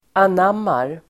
Uttal: [an'am:ar]